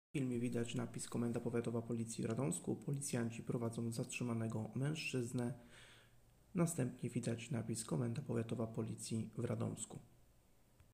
Nagranie audio Audiodeskrypcja